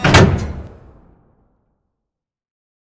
artillery-rotation-stop-2.ogg